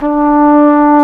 Index of /90_sSampleCDs/Roland L-CDX-03 Disk 2/BRS_Cornet/BRS_Cornet 2